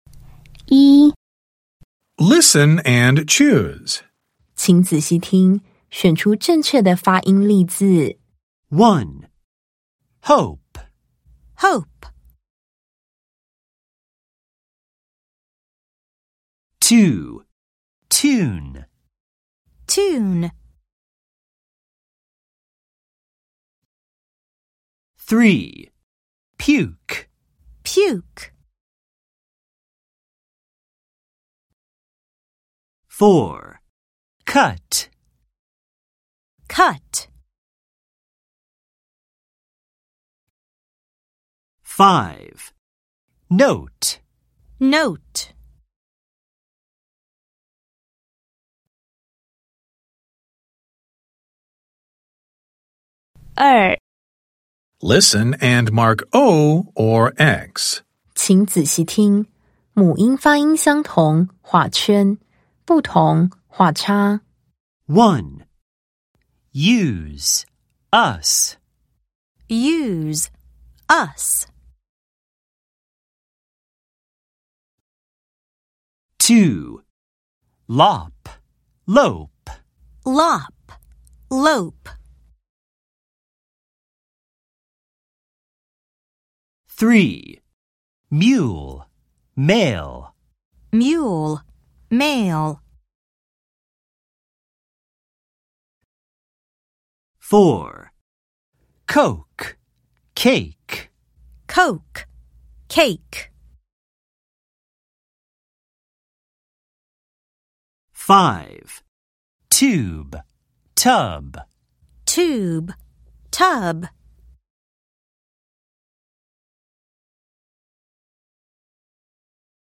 第2次定期評量_英語聽力測驗.mp3